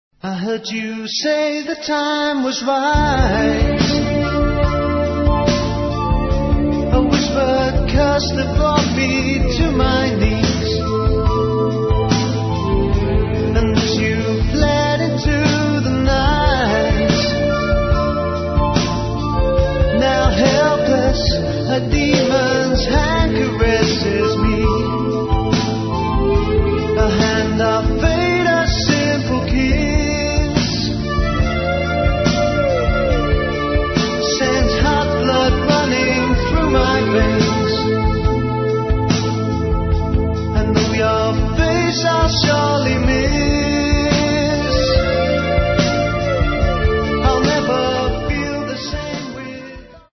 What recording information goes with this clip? Diploma Studio, Malden, Essex 1990.